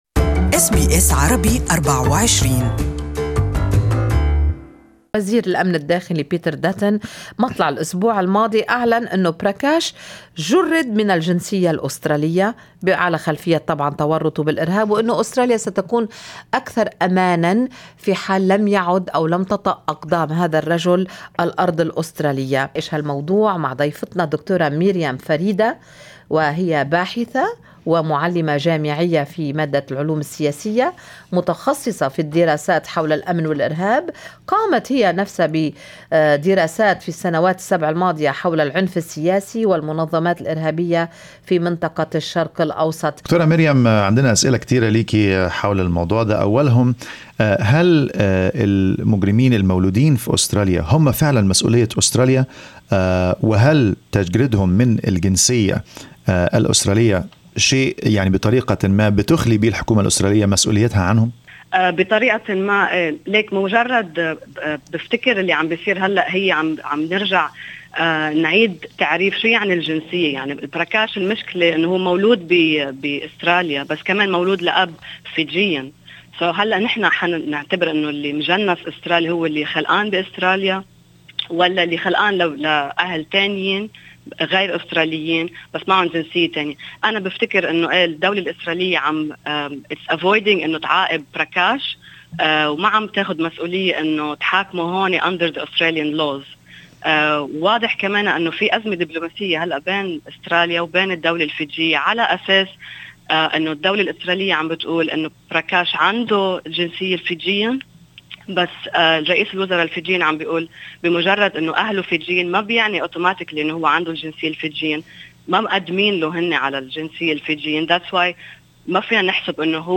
Listen to full interview in Arabic with Counter terrorism expert